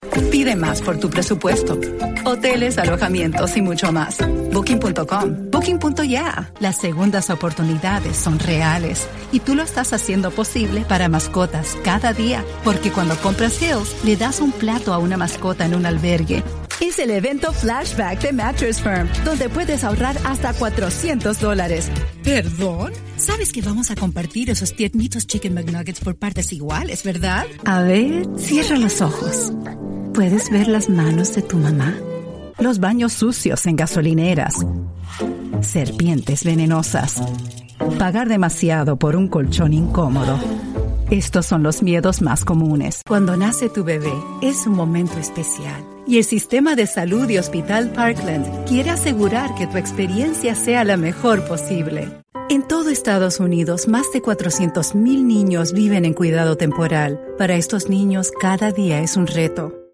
Demo comercial
Mis clientes describen mejor mi voz como amigable, chispeante, expresiva, agradable, cálida y entusiasta.